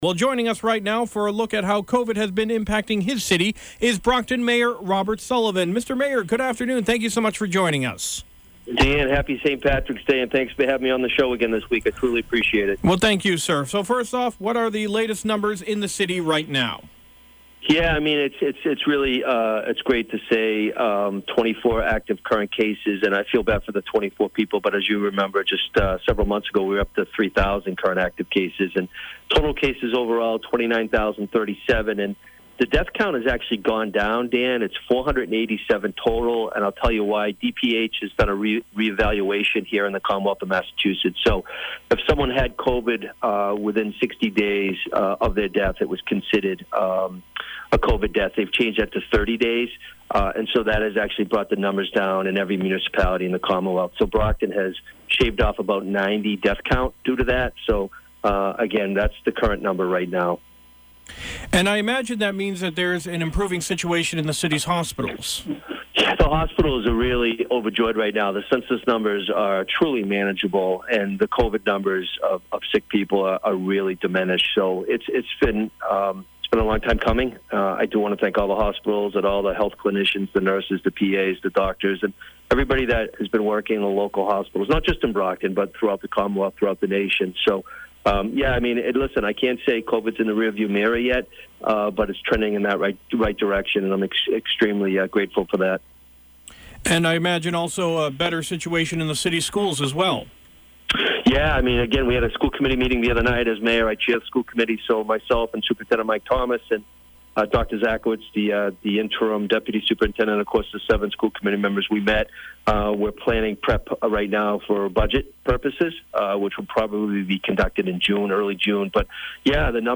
Brockton Mayor Robert Sullivan spoke